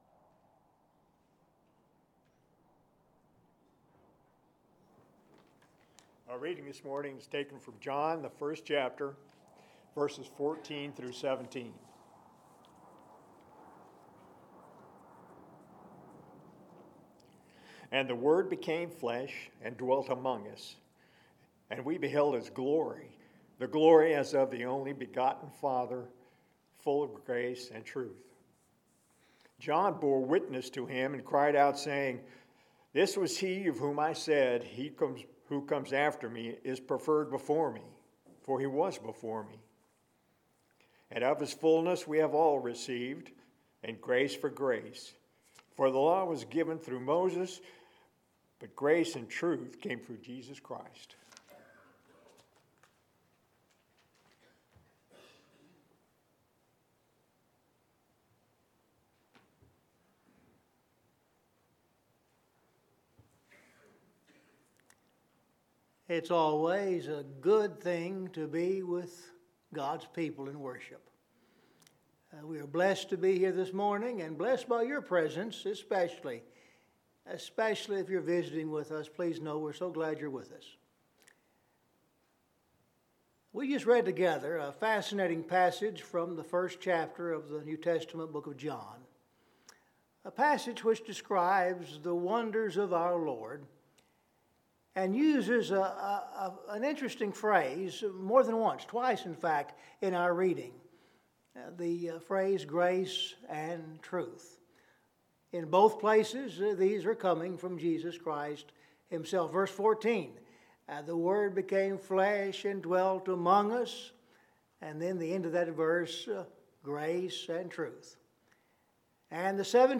10 am - Worship Assembly